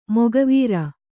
♪ moga vīra